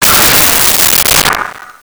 Rocket Launcher Sci Fi 02
Rocket Launcher Sci Fi 02.wav